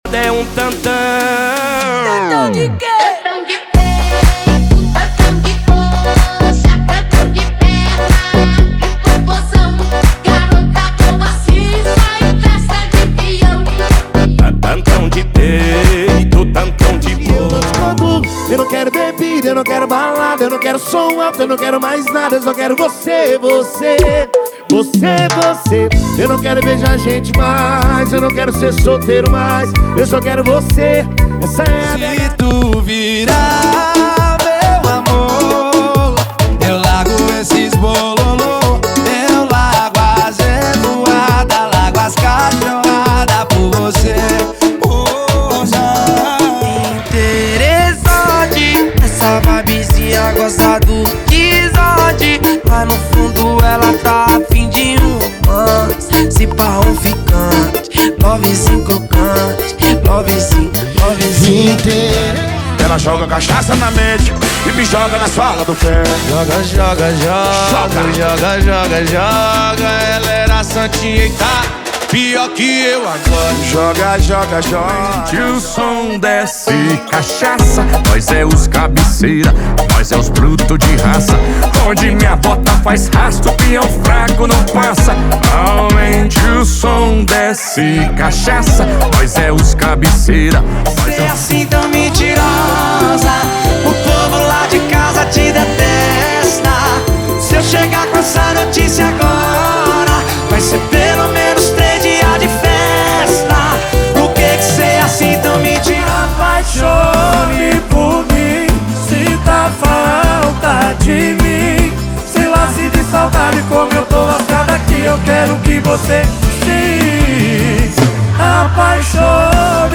Sertanejo: 80 Músicas
– Sem Vinhetas